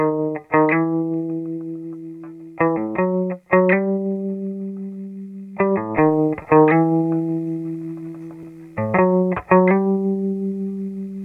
1 channel
guitar5.mp3